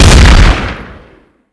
sol_reklam_link sag_reklam_link Warrock Oyun Dosyalar� Ana Sayfa > Sound > Weapons > WINCHESTER_1300 Dosya Ad� Boyutu Son D�zenleme ..
WR_Fire.wav